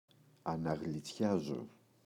αναγλιτσιάζω [anaγli’tsʝazo]